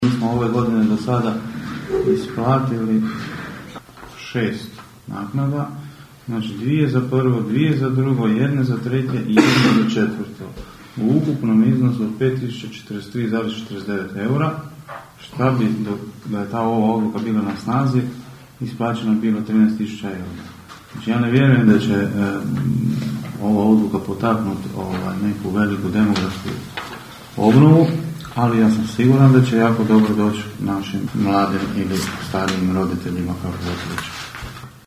Općinski načelnik Dean Močinić dodatno je pojasnio situaciju brojkama: (